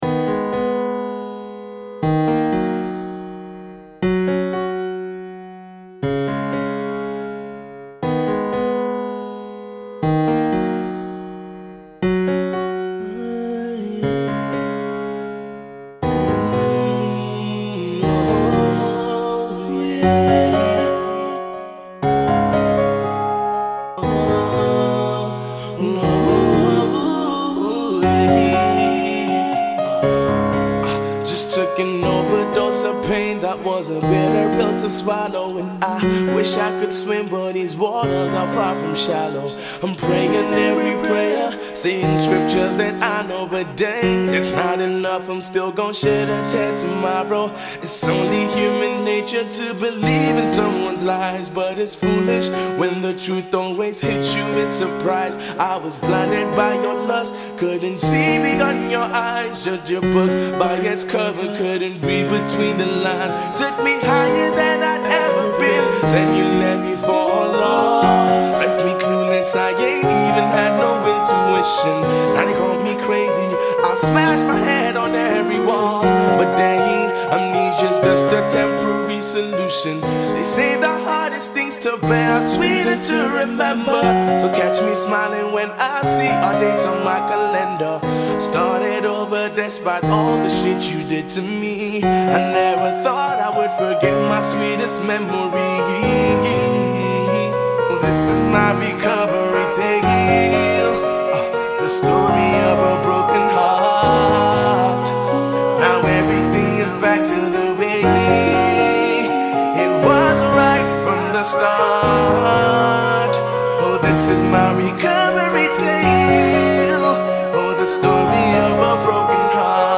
a talented singer/songwriter and rapper
croons softly on his current situation after a break-up
spoken word piece